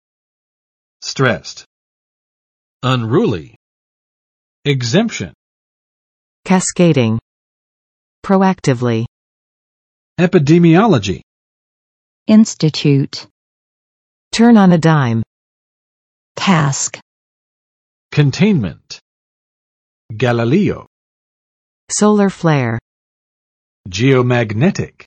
[strɛst] adj.（用作表语）紧张的，感到有压力的